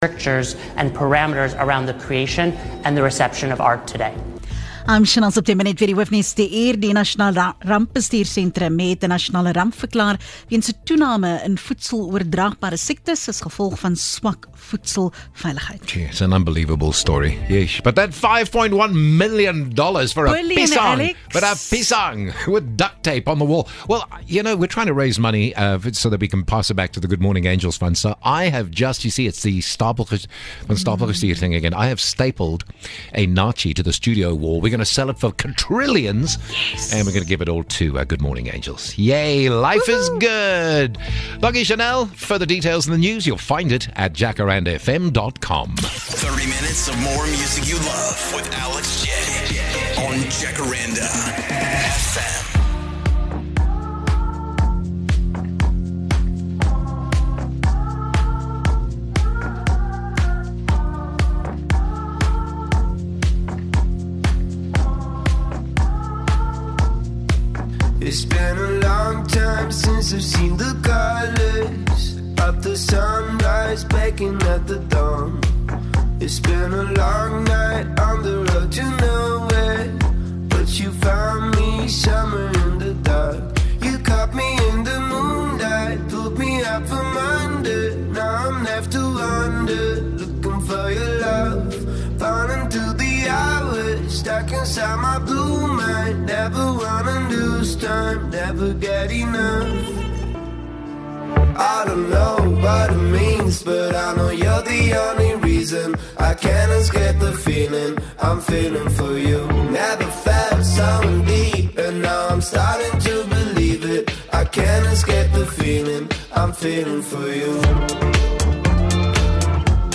Jacaranda FM News Bulletins
The Jacaranda FM News team is based in Gauteng – but covers local and international news of the day, providing the latest developments online and on-air. News bulletins run from 5am to 7pm weekdays, and from 7am to 6pm on weekends and public holidays.